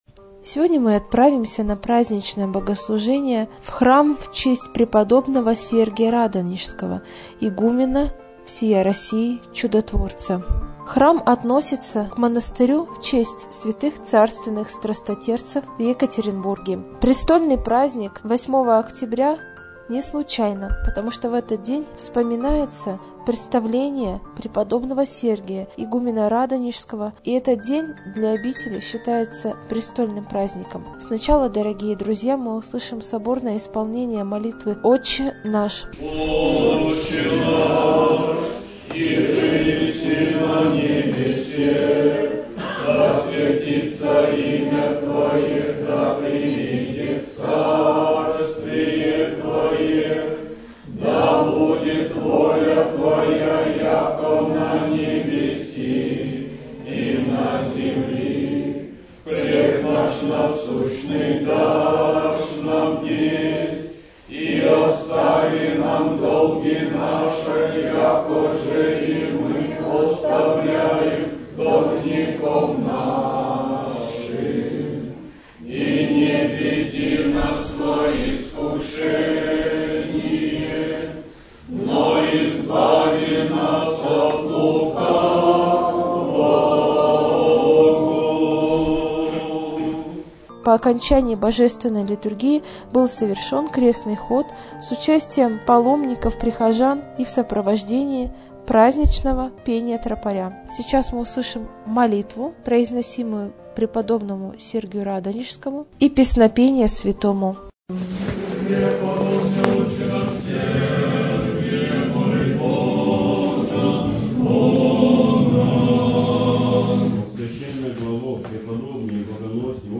Репортаж дня | Православное радио «Воскресение»
Престольный праздник храма Св. Сергия Радонежского
prestolnyj_prazdnik_hrama_sv_sergiya_radonezhskogo.mp3